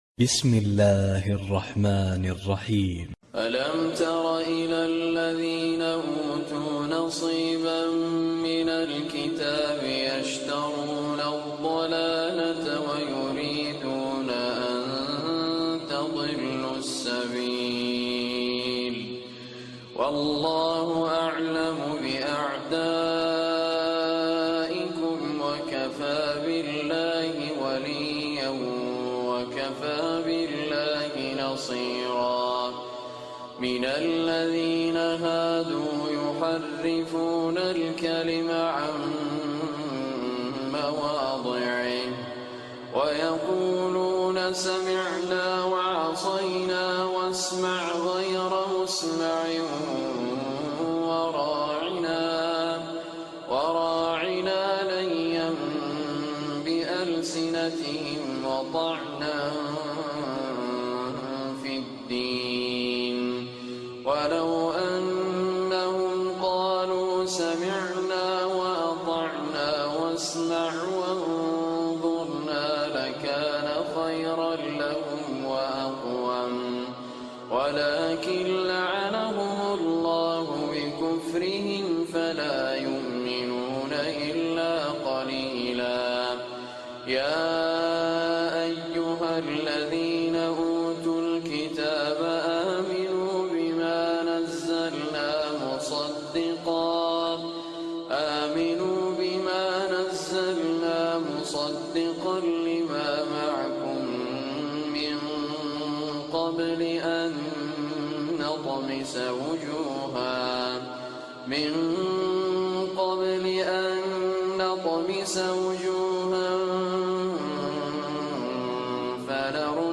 Surah An-Nisa Recitation by Raad Muhammad Al Kurdi